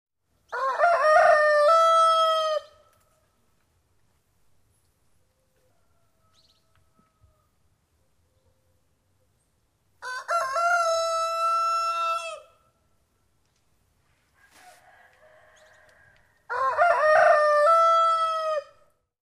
Звуки петуха в деревне на зорьке
• Категория: Петух
• Качество: Высокое